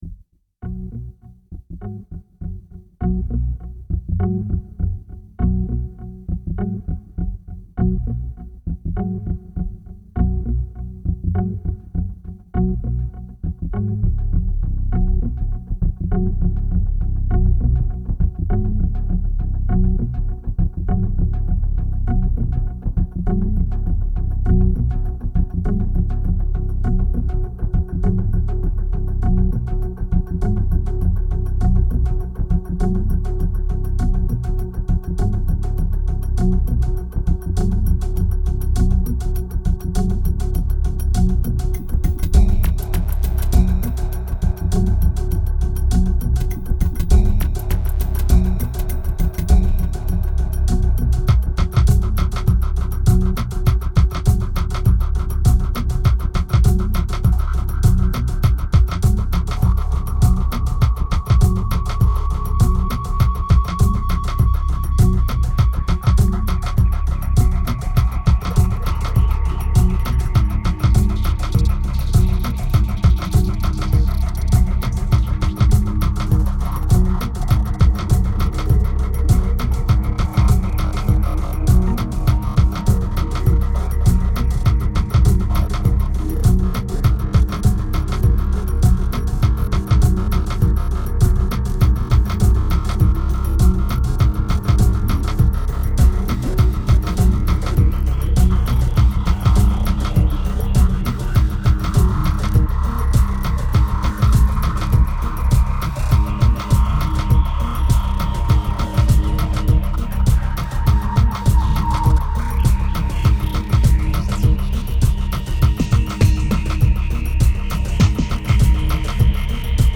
2151📈 - -13%🤔 - 101BPM🔊 - 2010-11-01📅 - -180🌟